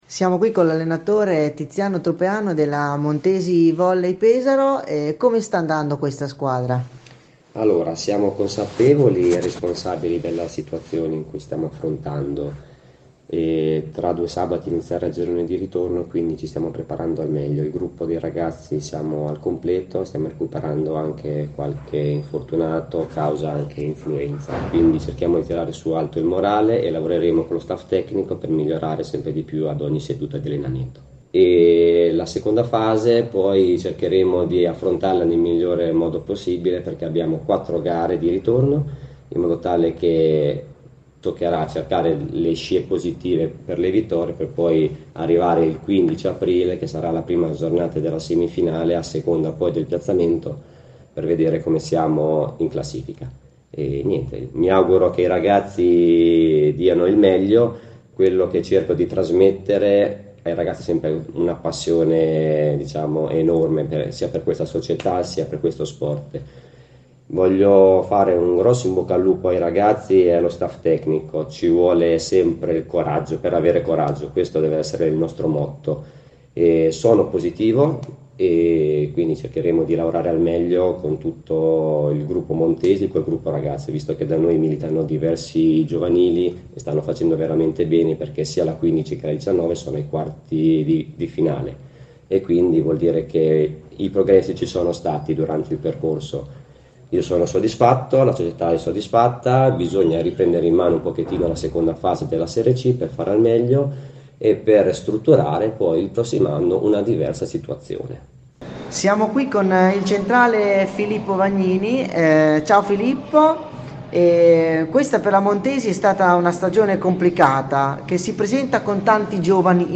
interviste-montesi-volley.mp3